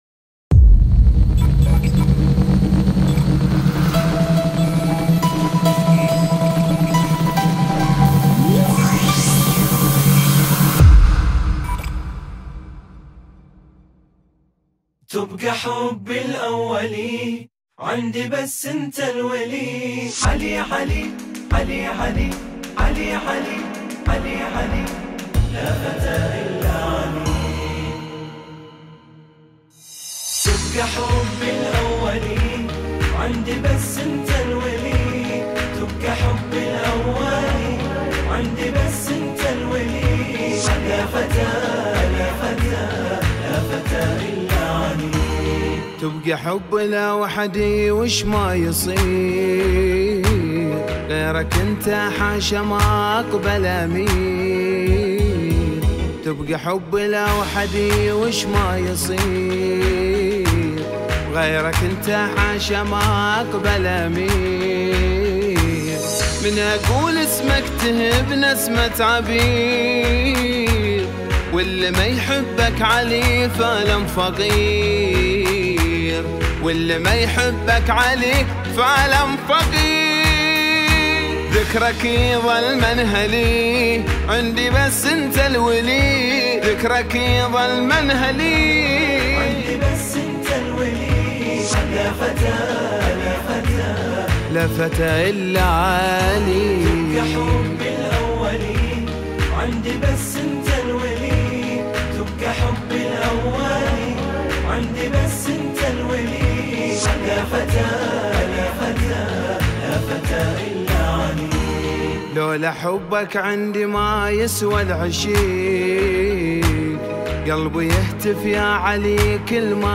في ذكرى عيدالغدير